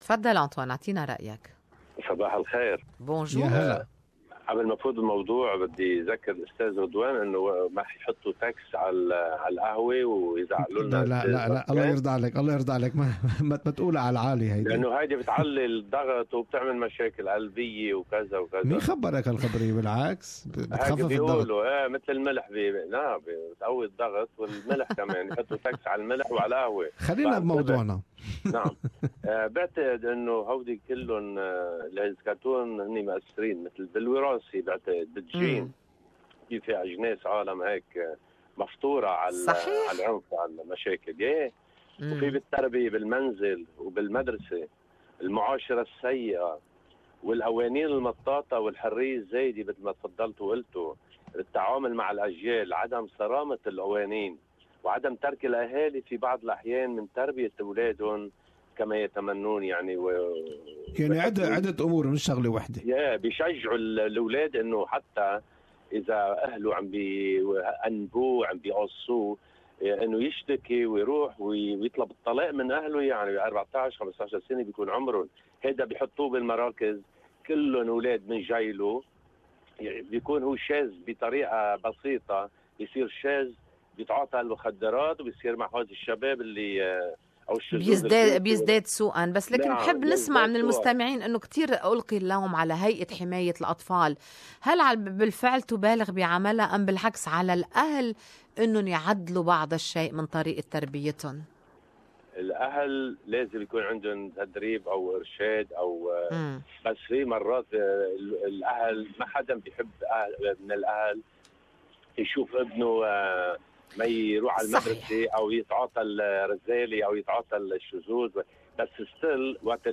Talkback: Why do some refugees join street gangs in Australia?